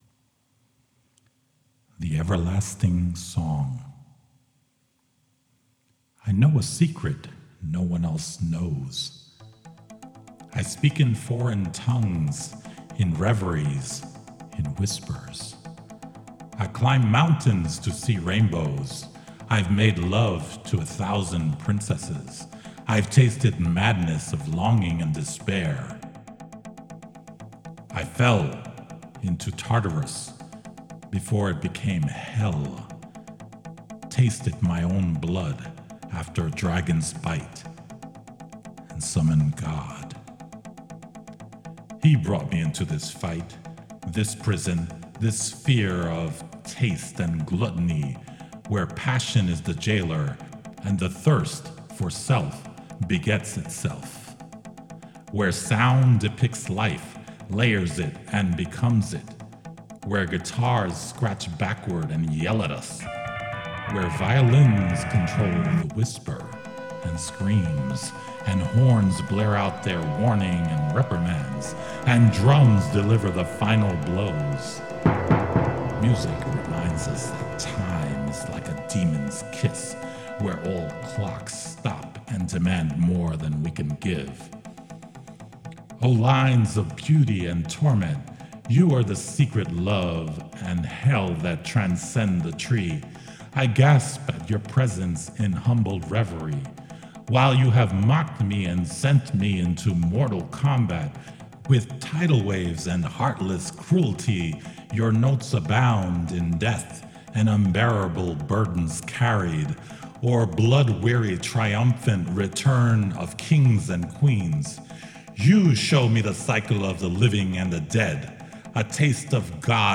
an audio poem about music